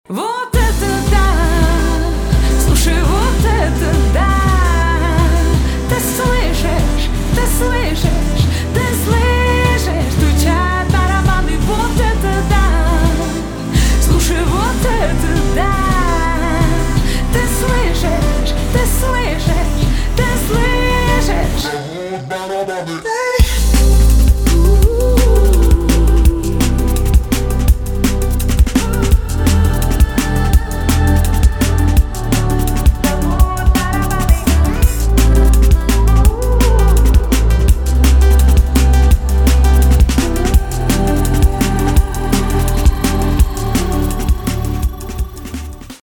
поп
drum n bass
Liquid DnB